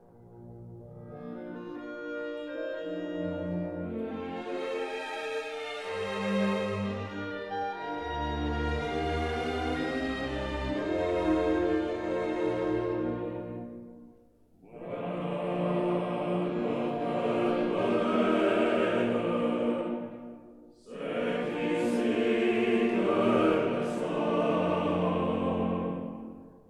soprano
tenor
baritone
bass
Chorus and orchestra of the